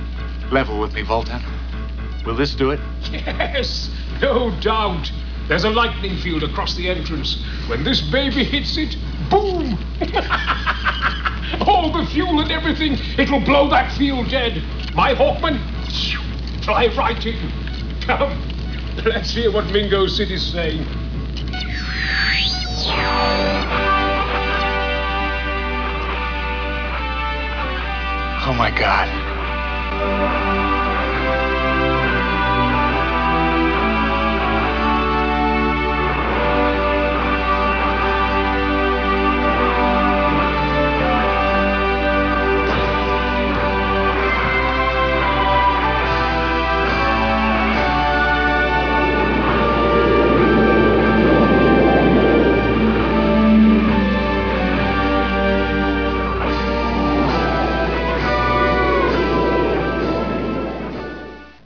Anthem.wav